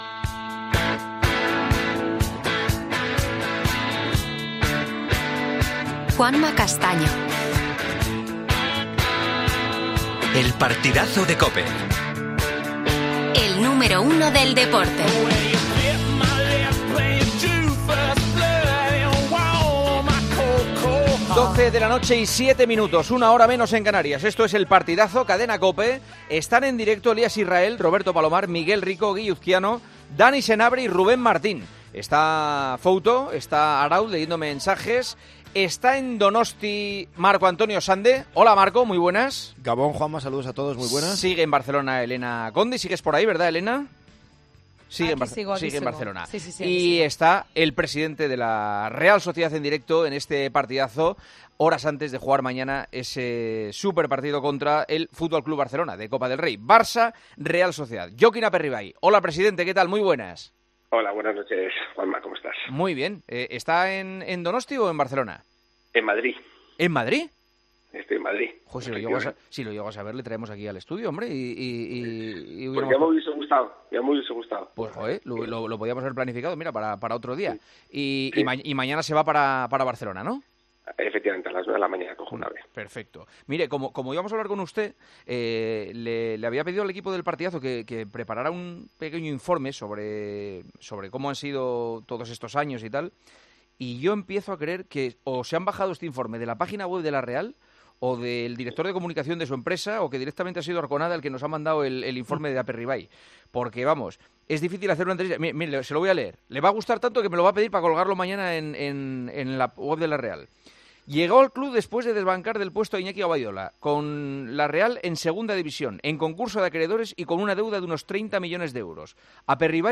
AUDIO - ESCUCHA LA ENTREVISTA A JOKIN APERRIBAY, EN EL PARTIDAZO DE COPE